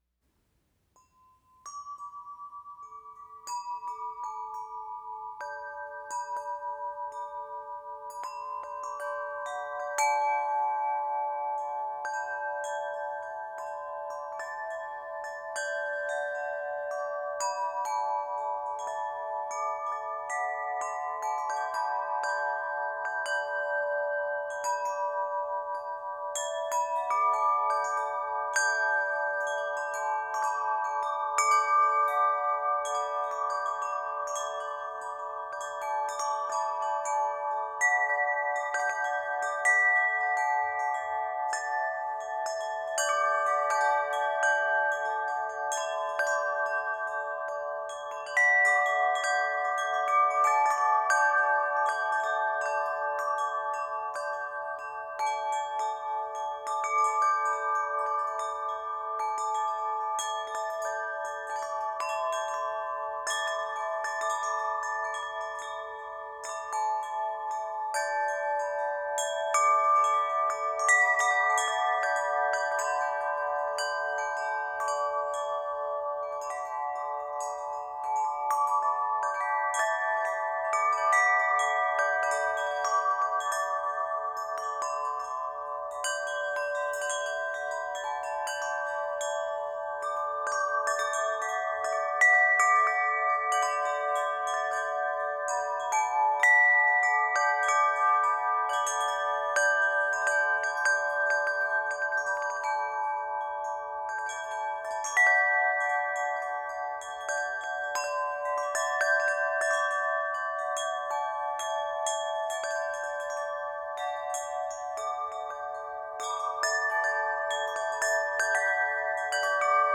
créés à partir de bols tibétains et cloches.